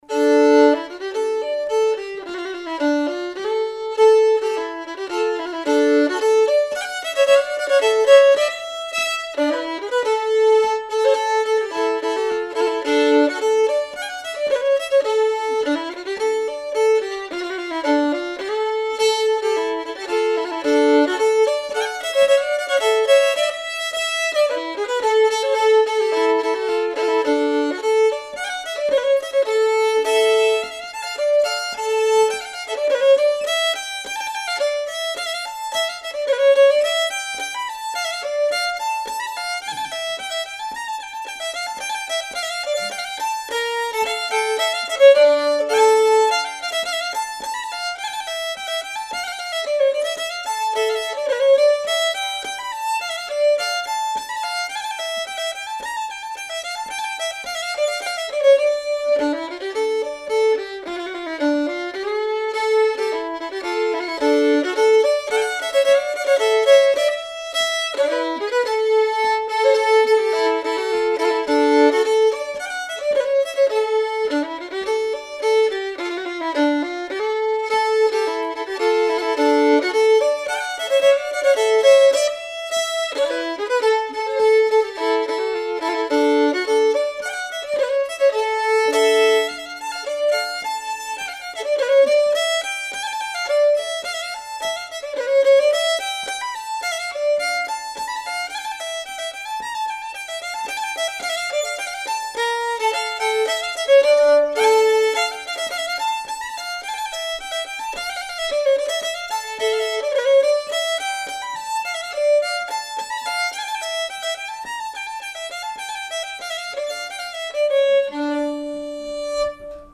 Flugsvampen Polska e Blank Anders